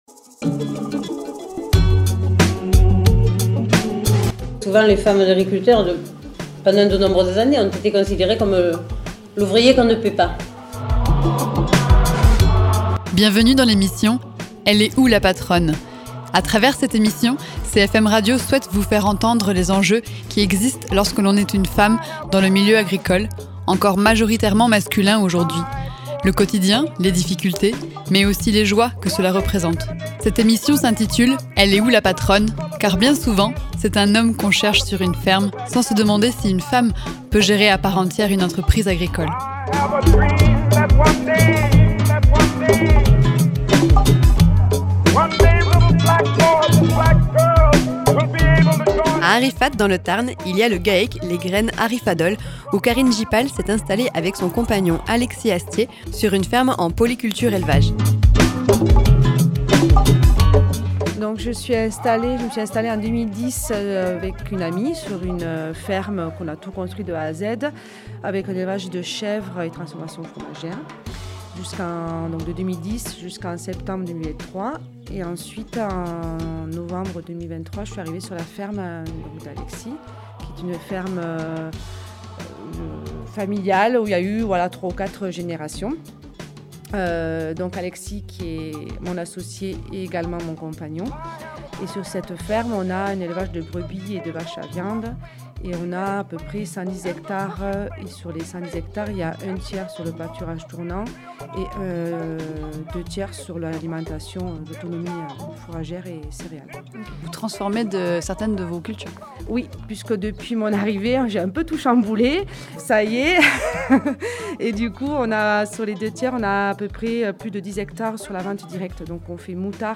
Émissions
agricultrice